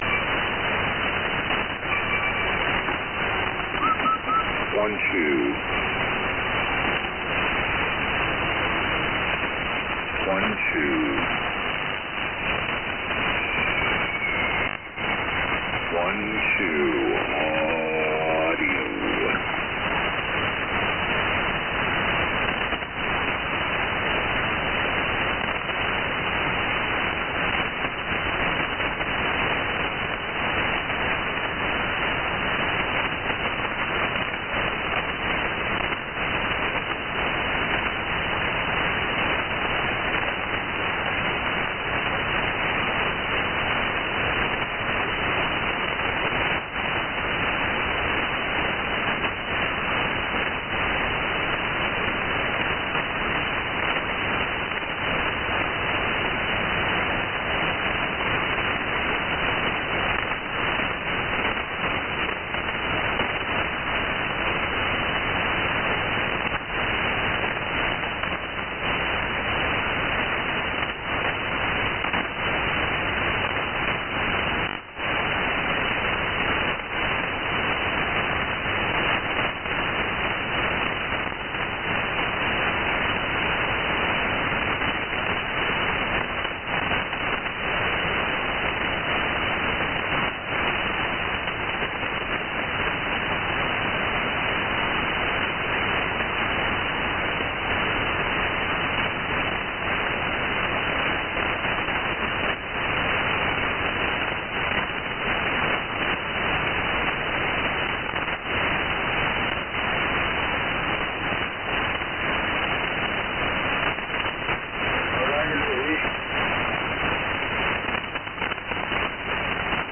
SDR recording catch. Heard from 2238 with test counts, then 2242 sign on with music. Then the OM starting speaking around 2256.
Lost under UTE QRM at 2346.